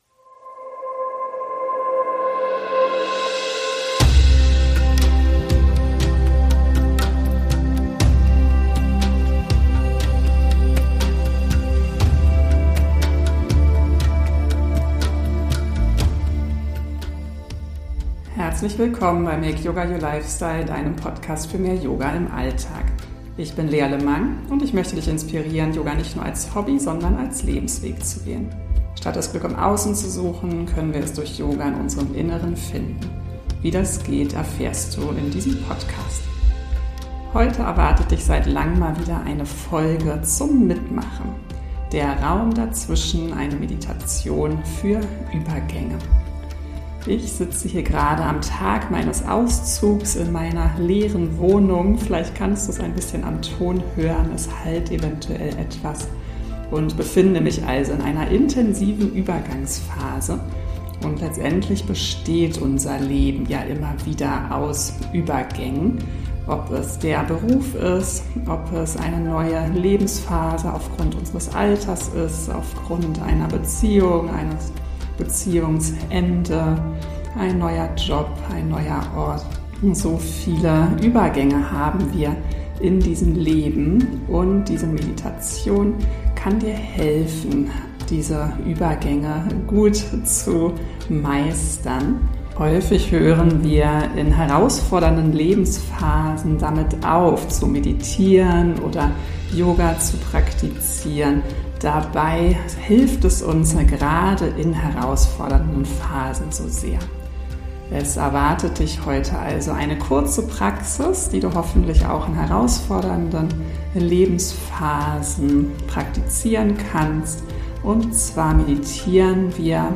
In dieser kurzen, stillen Meditation begleite ich dich in den Raum zwischen zwei Atemzügen – den Moment, in dem weder Ein- noch Ausatmung geschieht.